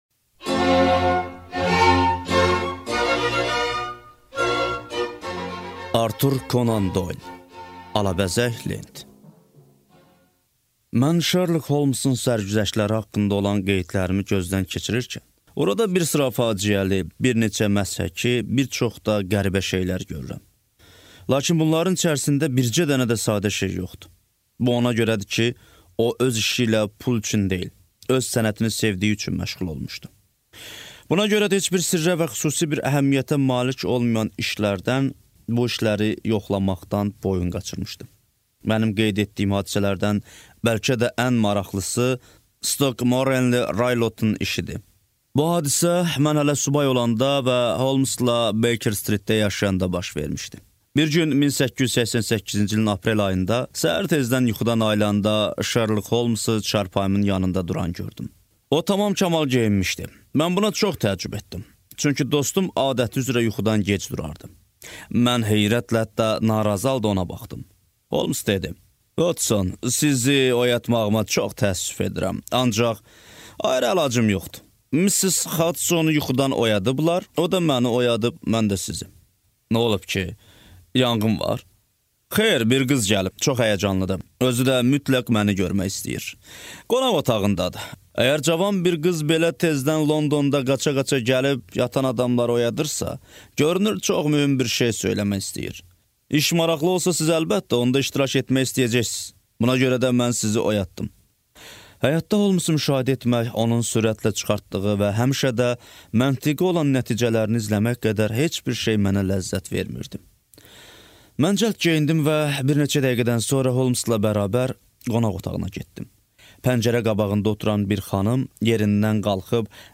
Аудиокнига Artur Konan Doylun hekayələri | Библиотека аудиокниг